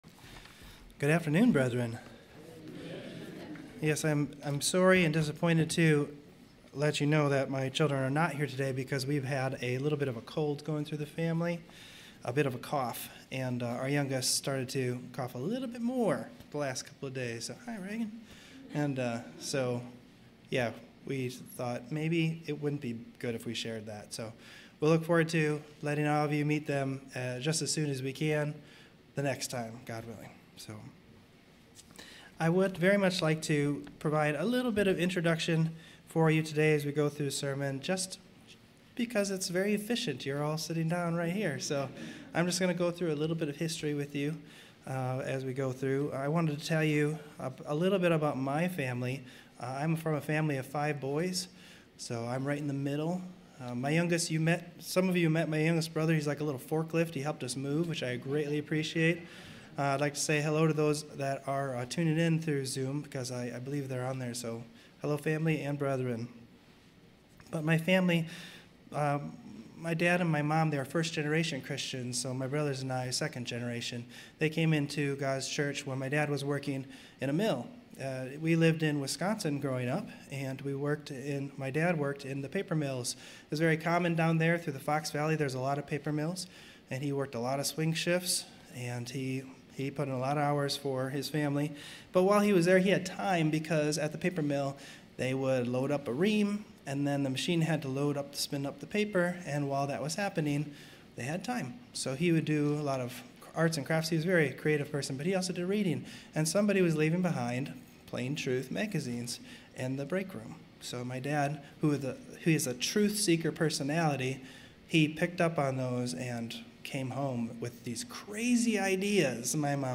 sermon_the_vision_of_seeking_first_the_kingdom.mp3